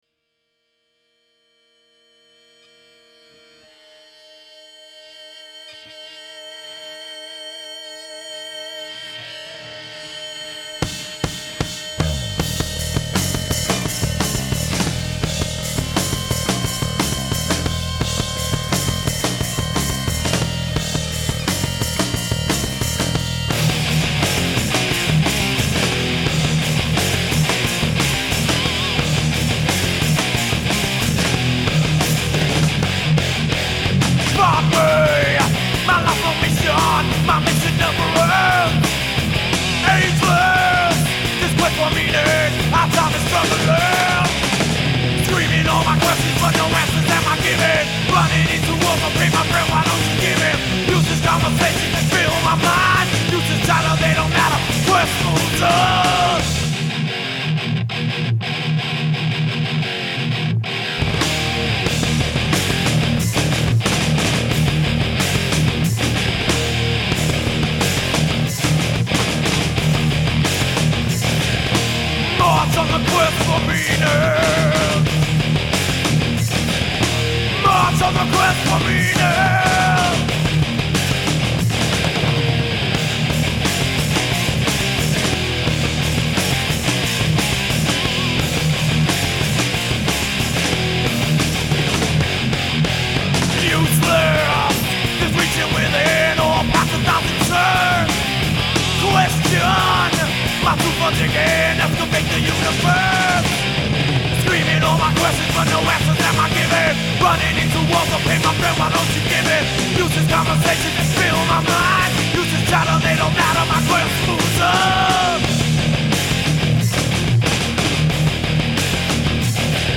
Bass
Alien Flyers Studios, New York, NY